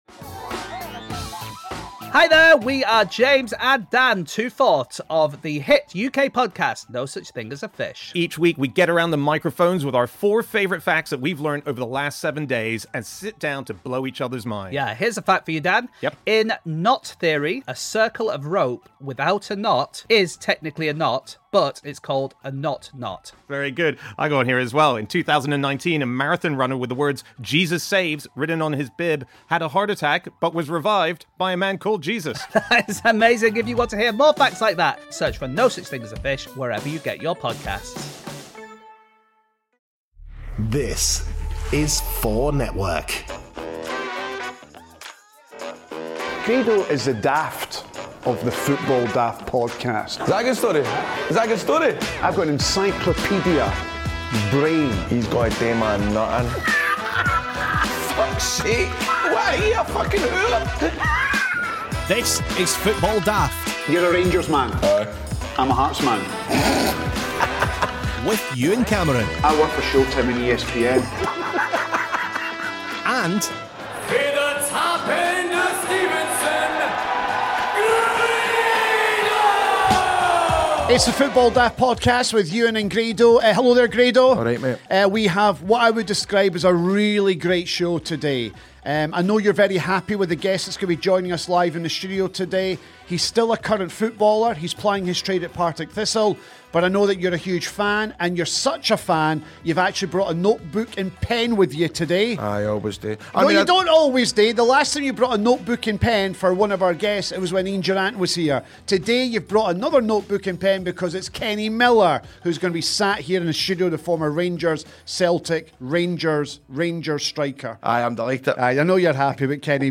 in the studio for Episode 20.